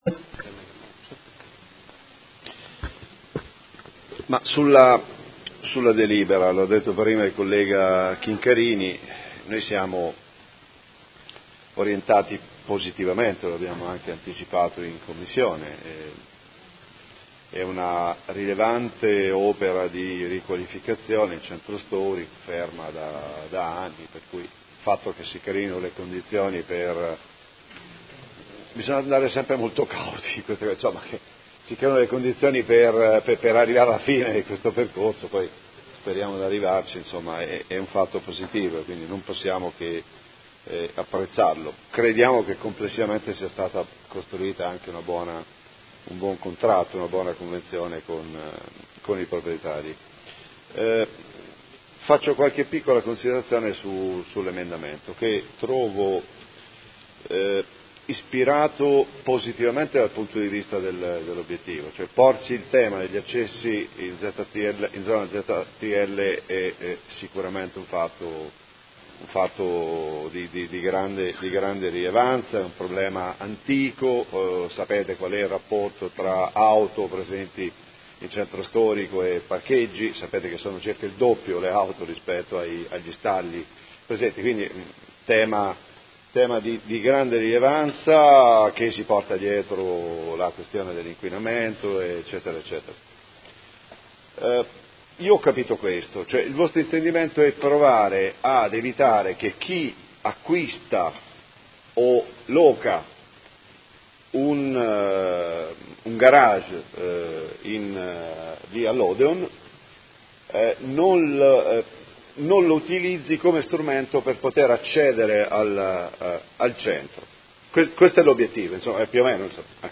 Seduta del 19/07/2018 Dibattito.
Audio Consiglio Comunale